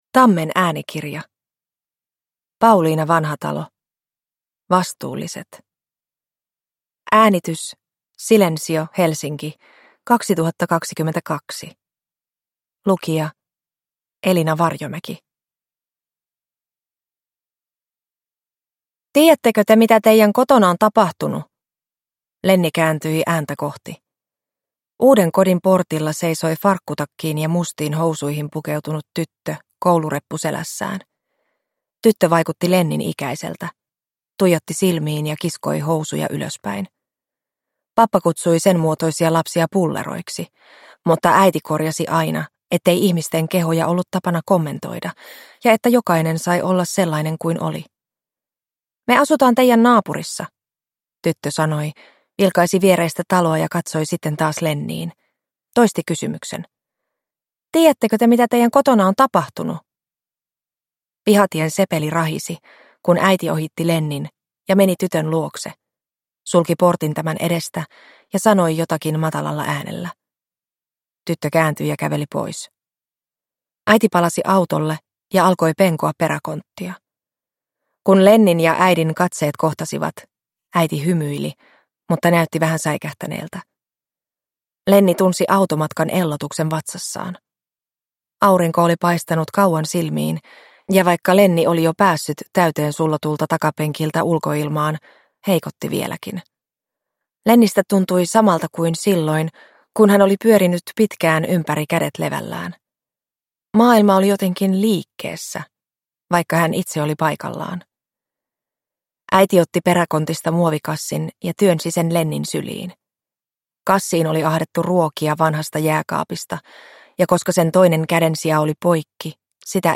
Vastuulliset (ljudbok) av Pauliina Vanhatalo